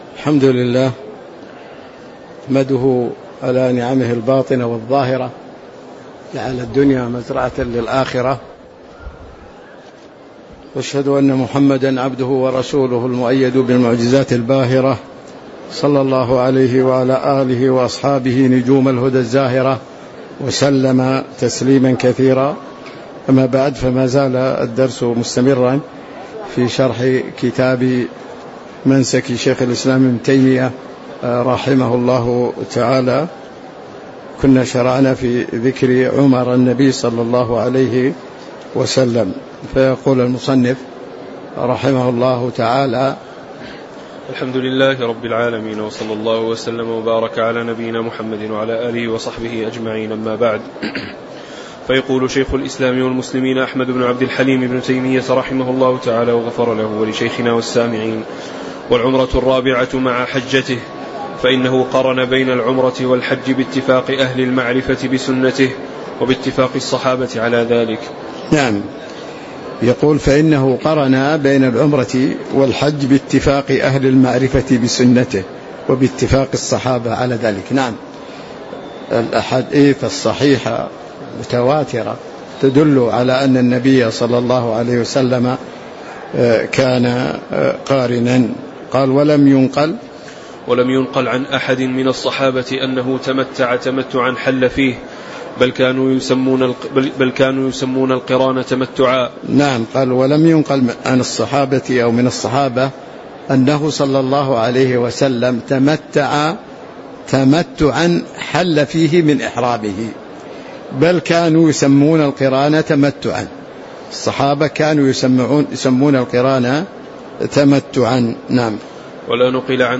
تاريخ النشر ٢٨ ذو القعدة ١٤٤٦ هـ المكان: المسجد النبوي الشيخ